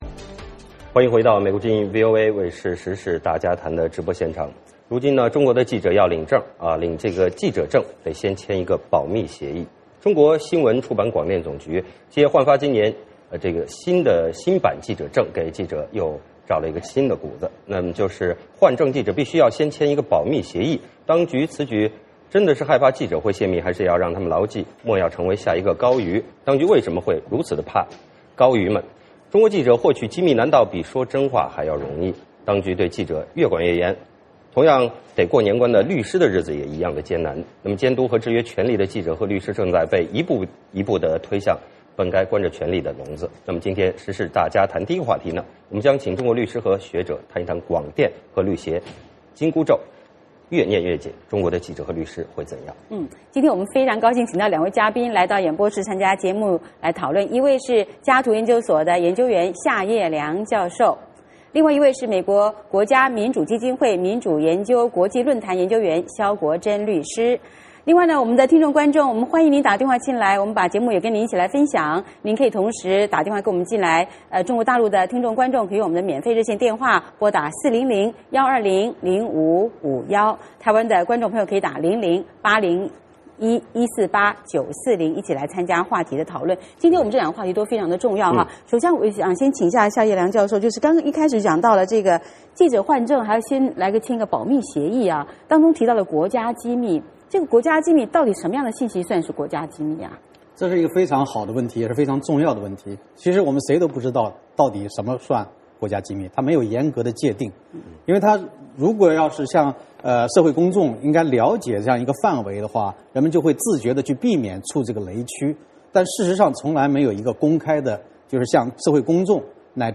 今天时事大家谈第一个话题，我们请中国律师和学者谈谈广电和律协紧箍咒越念越紧，中国的记者和律师会怎样？